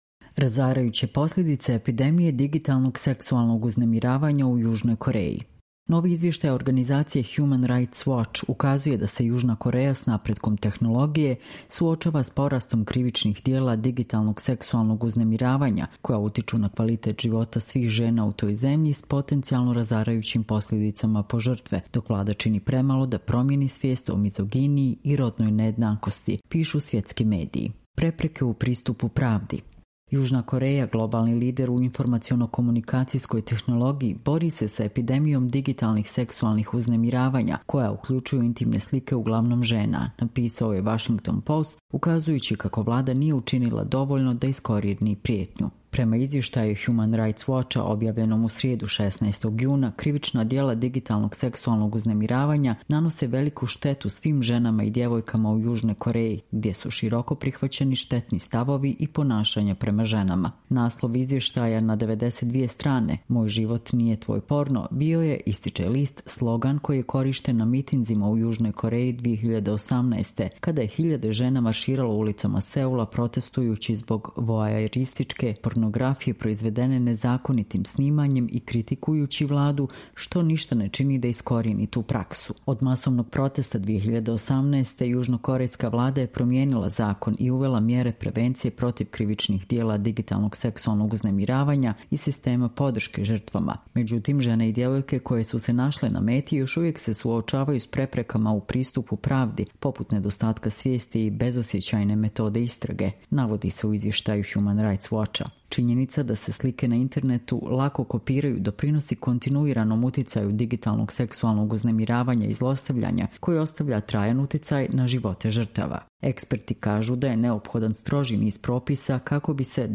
Čitamo vam: Razarajuće posljedice ‘epidemije’ digitalnog seksualnog uznemiravanja u Južnoj Koreji